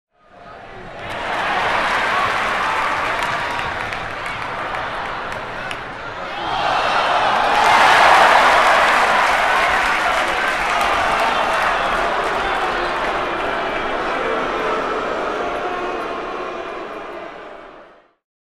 Звуки спорта
Баскетбол, громкие овации и недовольные возгласы болельщиков